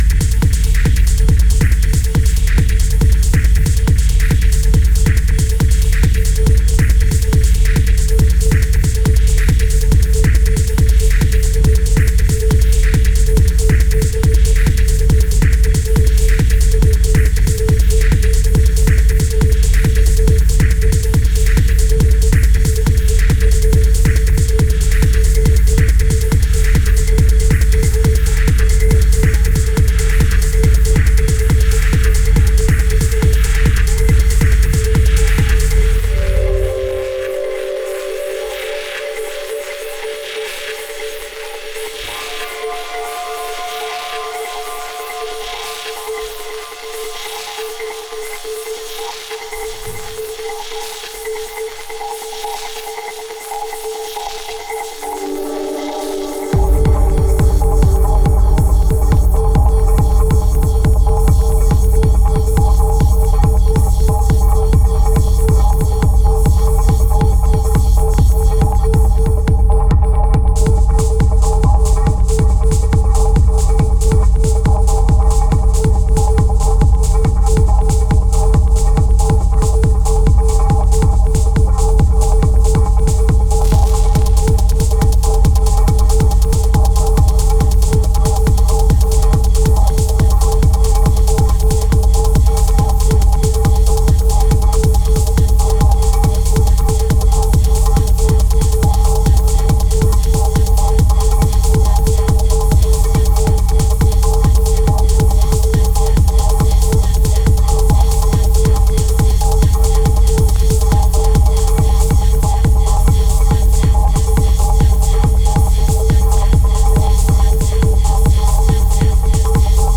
Techno
a new VA series focusing on deep explorative techno.